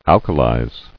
[al·ka·lize]